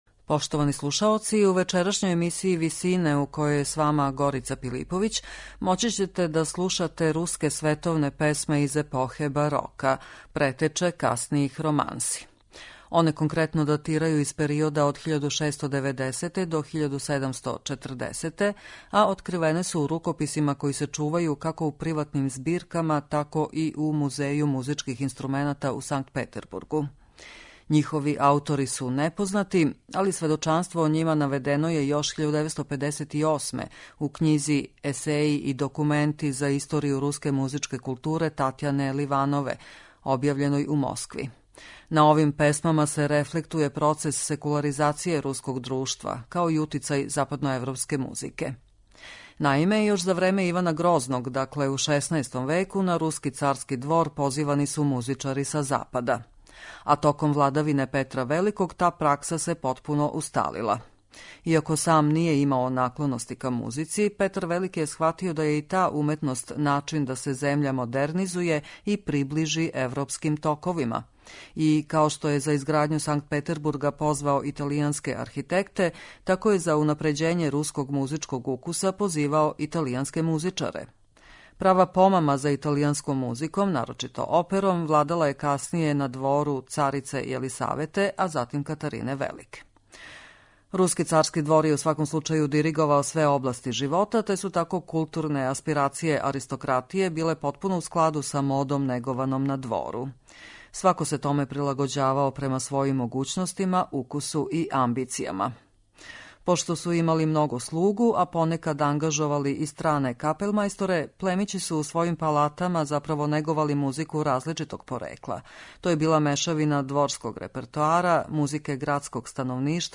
У емисији Висине можете слушати руске солопесме из периода барока.
Руске барокне романсе